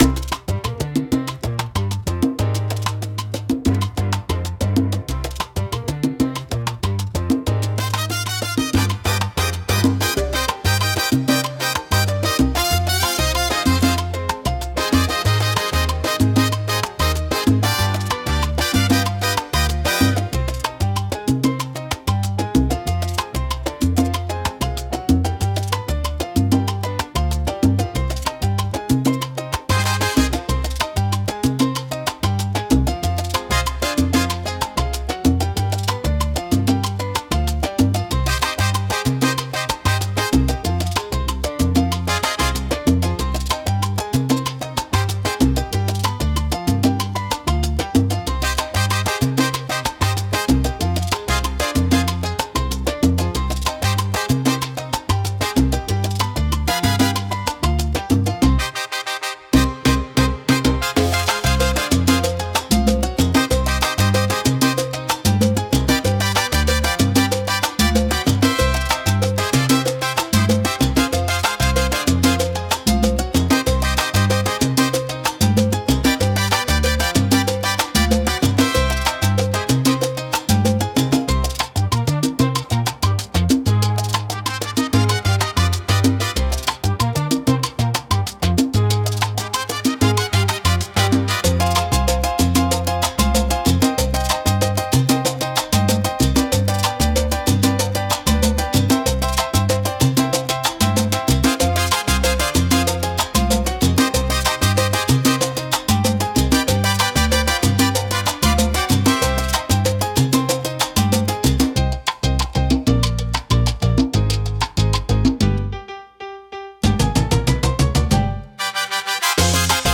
聴く人に活力と情熱を与え、明るく熱気に満ちた空間を作り出します。